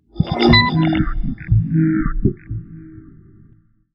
UI_SFX_Pack_61_56.wav